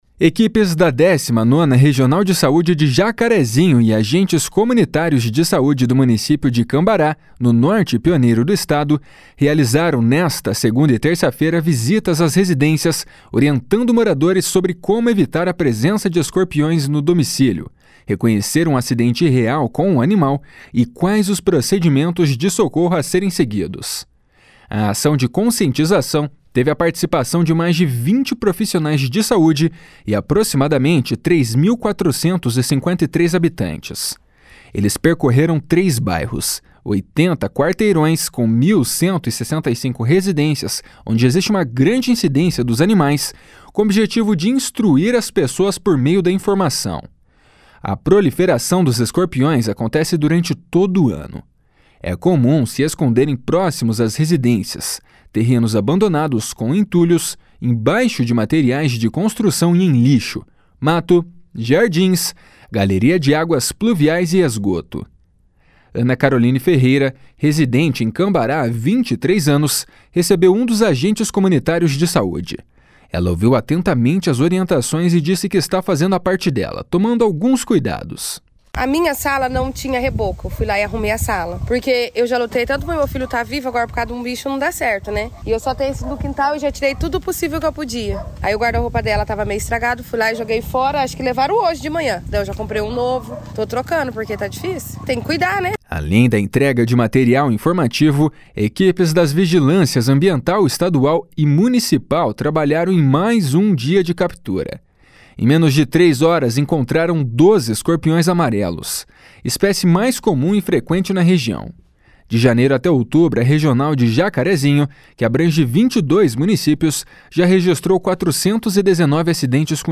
Repórter: